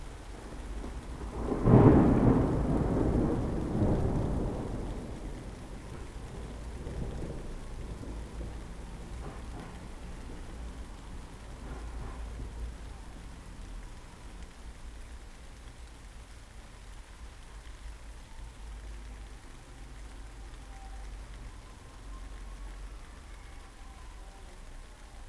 enviro_thunder_8.wav